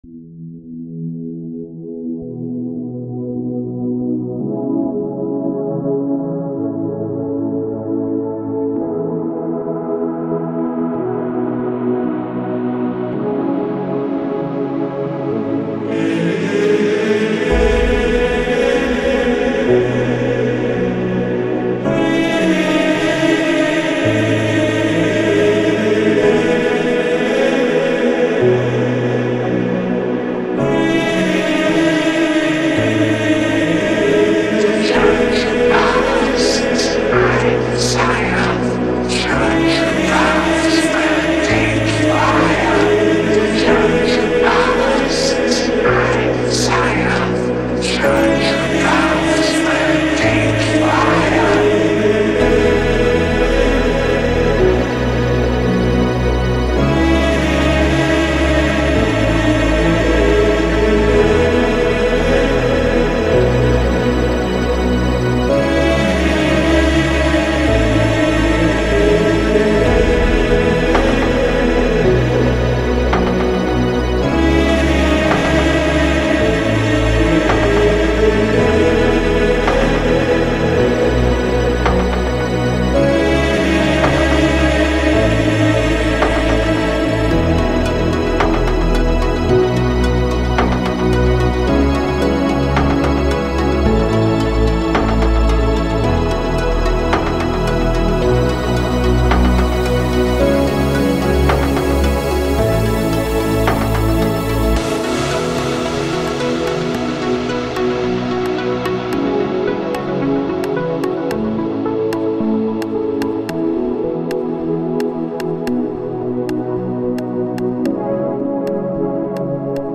• Жанр: Dance